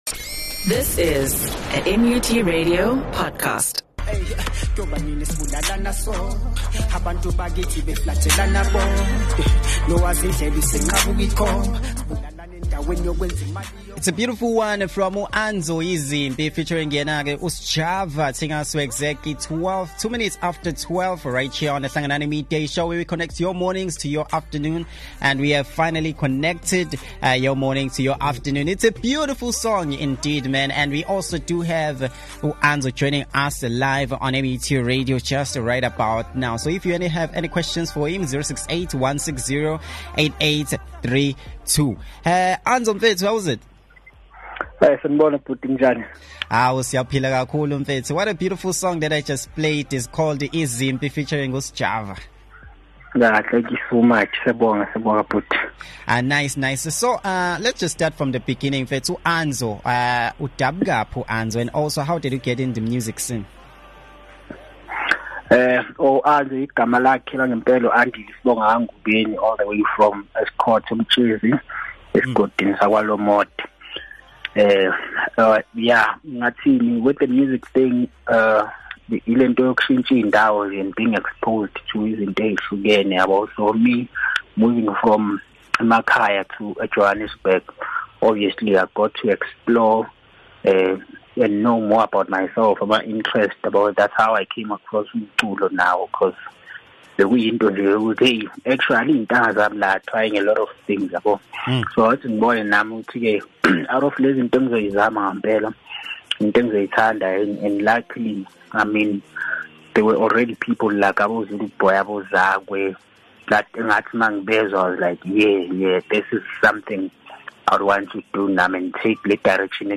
During the interview, he also revisited the energy and impact of his Back To The City performance, highlighting it as a defining moment.